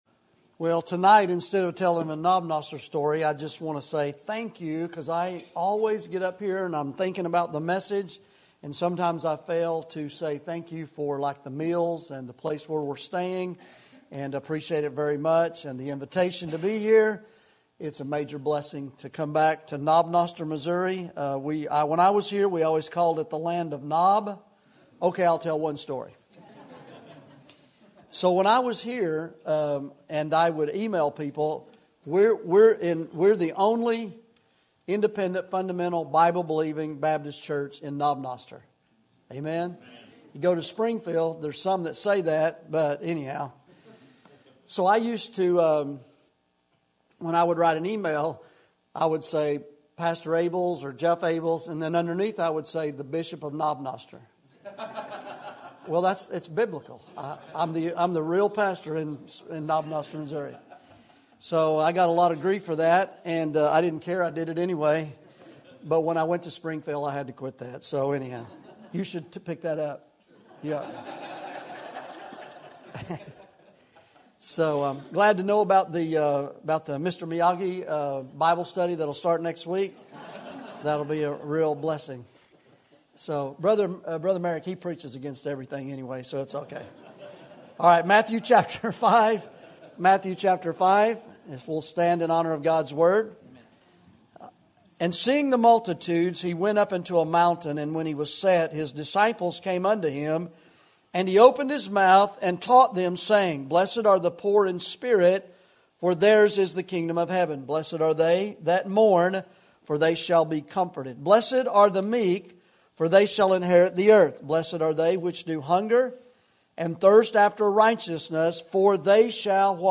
Sermon Topic: Winter Revival Sermon Type: Special Sermon Audio: Sermon download: Download (17.45 MB) Sermon Tags: Matthew Revival Beatitudes Salt